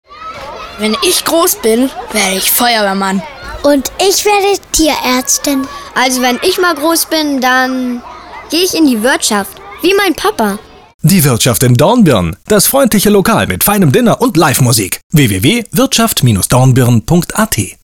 Hörfunk
zurgams hoerspots radio